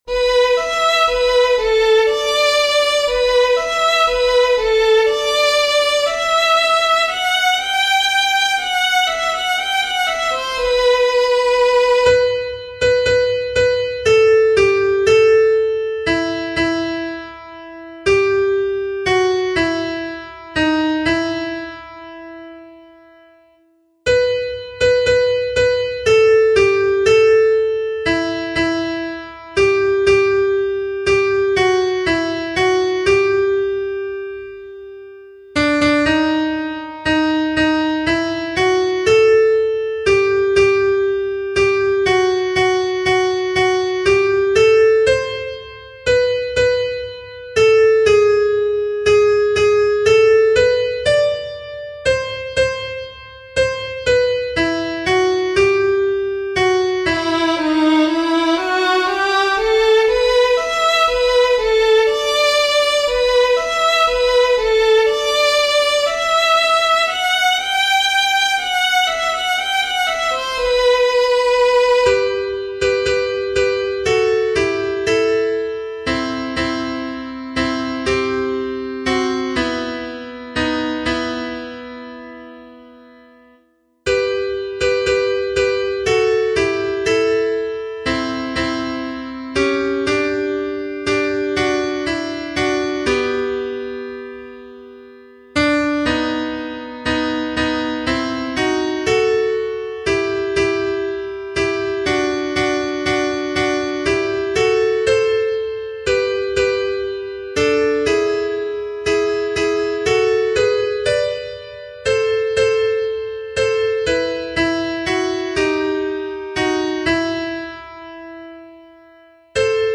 Note: Violin solos are included. Voice parts are played on a piano.